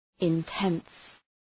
{ın’tens}
intense.mp3